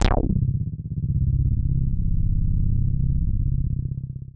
C2_moogy.wav